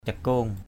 /ca-ko:ŋ/ 1.
cakong.mp3